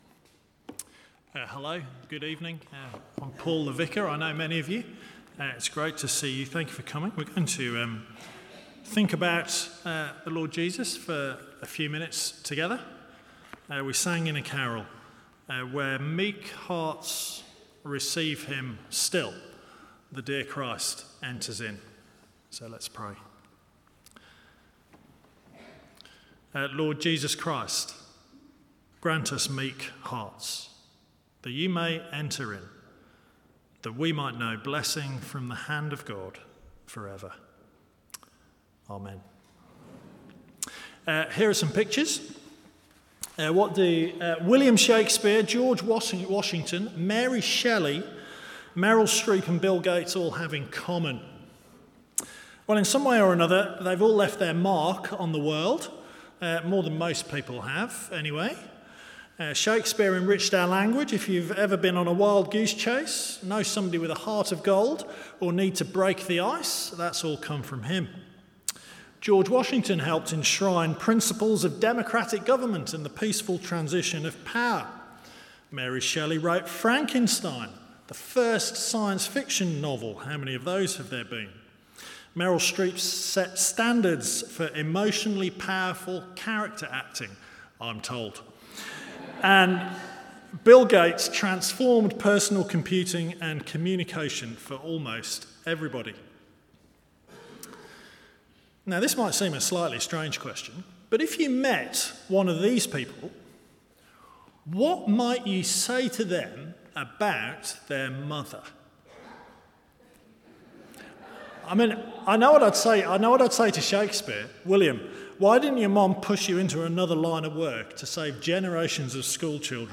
Sermons from Holy Trinity Church, Oswestry